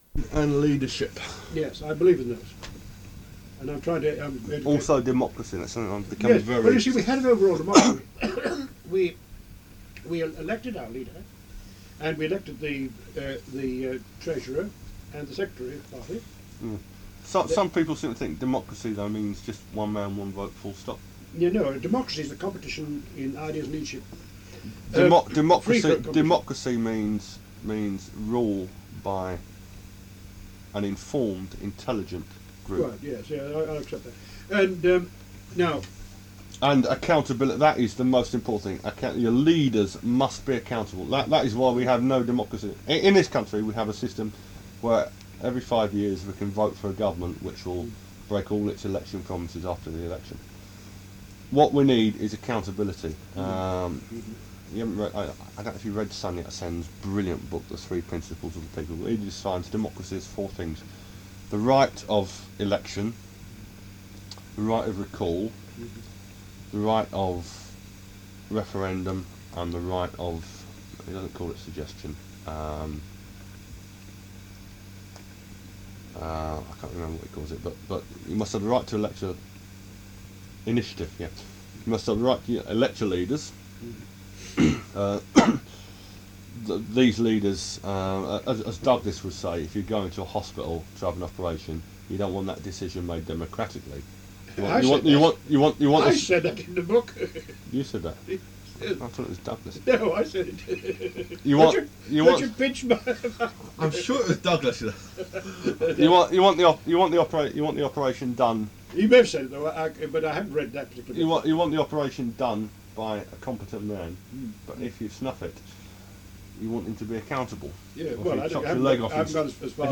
FINANCIALREFORM: INTERVIEW